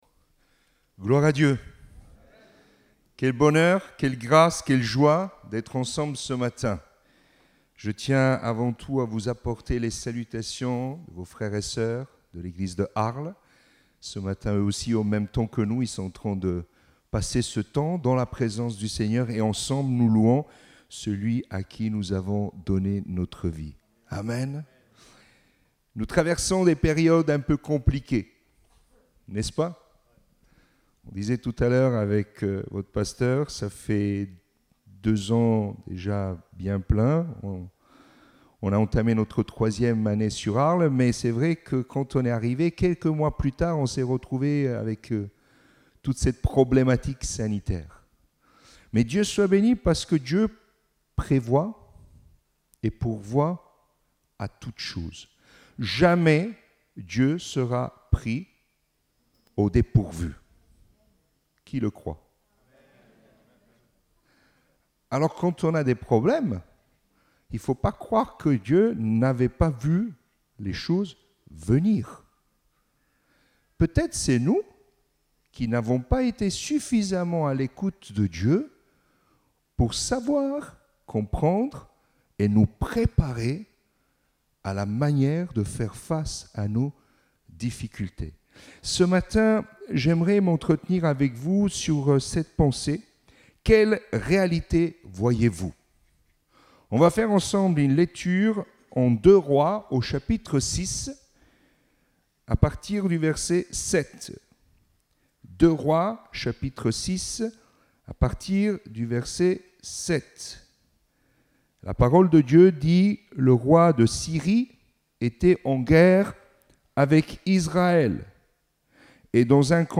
Date : 14 novembre 2021 (Culte Dominical)